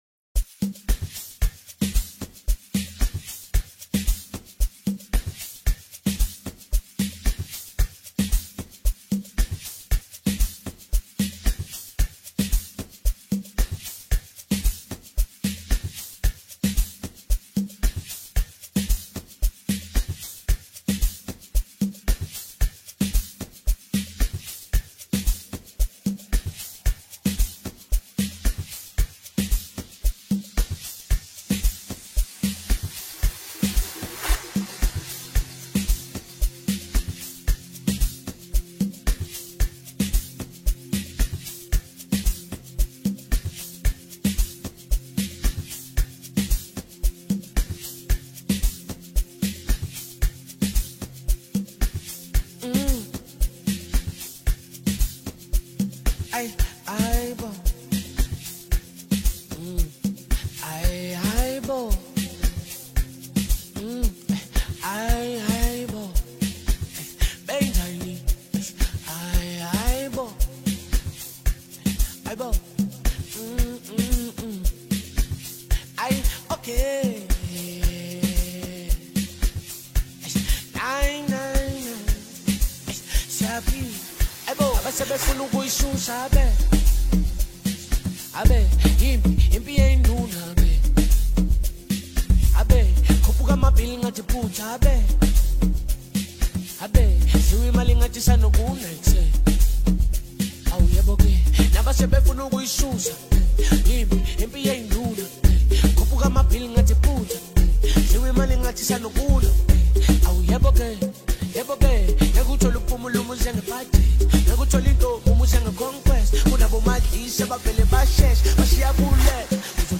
Soulful piano
a dose of Soulful flairs